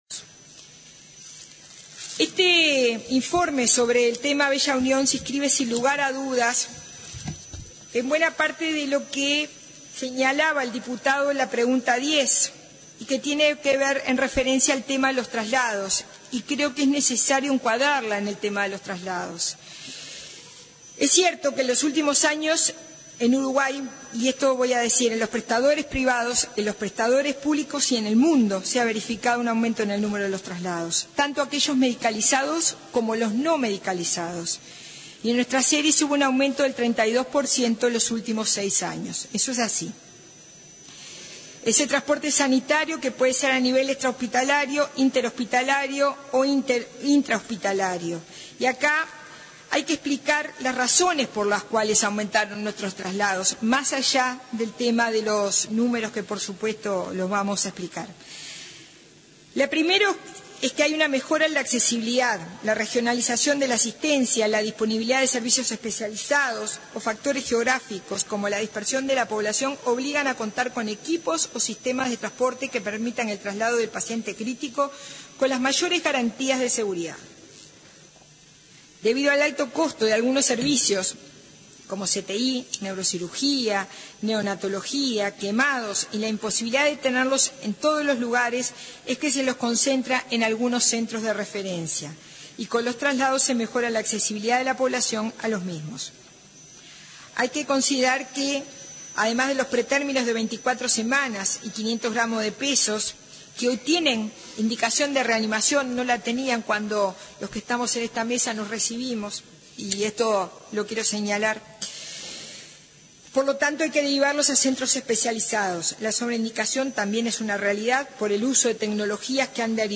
La presidenta de ASSE, Susana Muñiz, subrayó que existe en el mundo un aumento de traslados sanitarios y que en Uruguay aumentaron 32 % en los últimos seis años. Subrayó en el Parlamento que la mejora en accesibilidad de los pacientes a centros de referencia y la mayor utilización de camas de CTI explican dicho incremento.